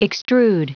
Prononciation du mot extrude en anglais (fichier audio)
Prononciation du mot : extrude